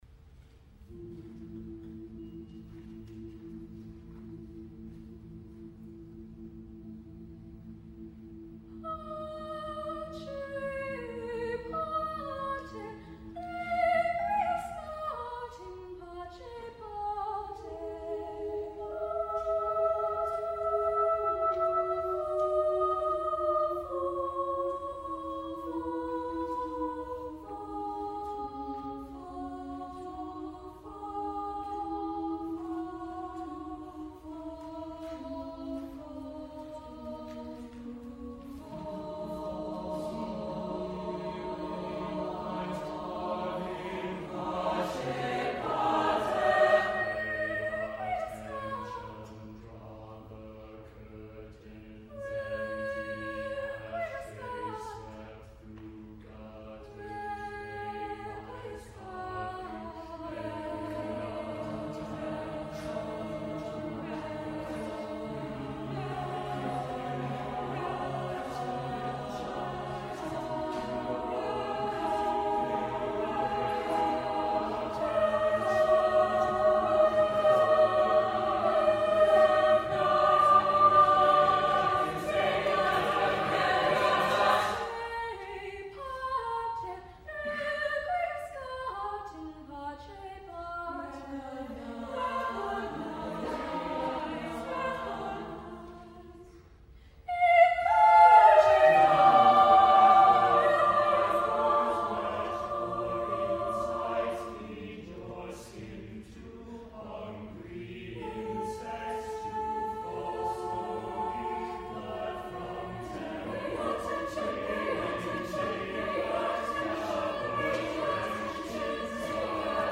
The idea was that I would write an active texture for the chorus to support a lead singer and maybe stick to a relatively identifiable song form while screwing around liberally with harmonies and arrangement.
"Towers" concert recording